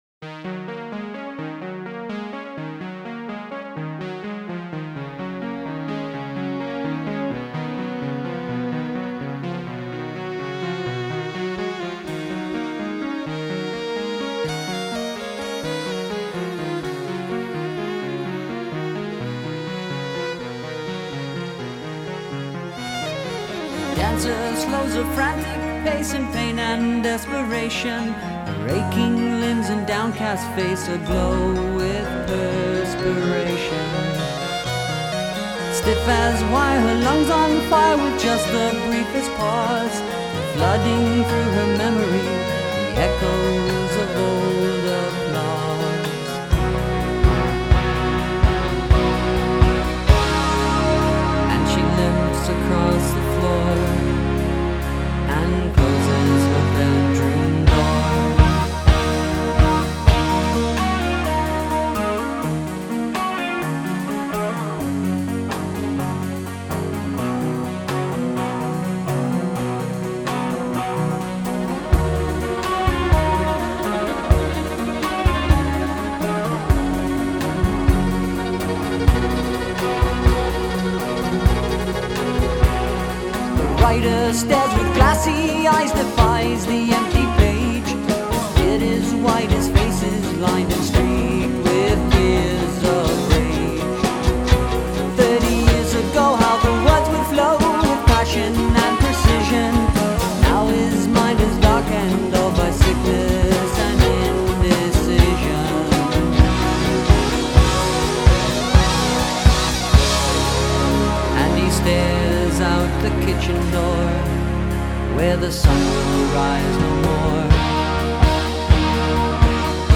beautiful electric violin